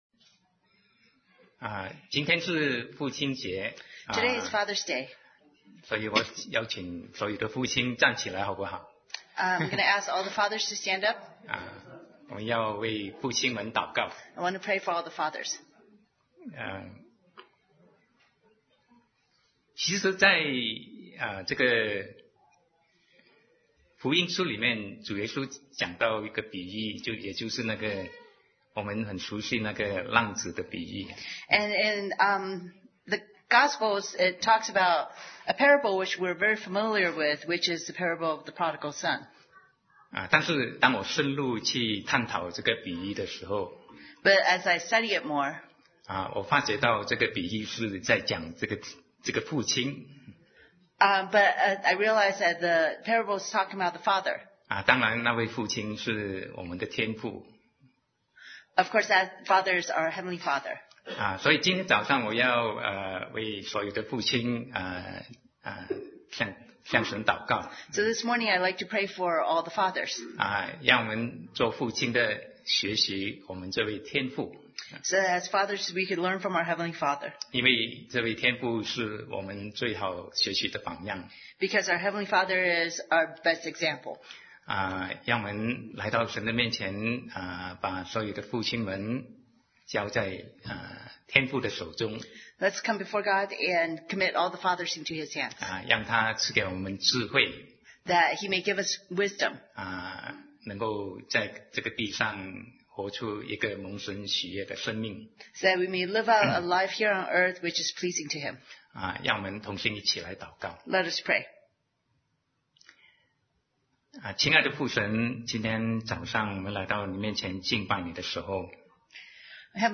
Sermon 2019-06-16 Are You a Christian?